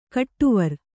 ♪ kaṭṭuvar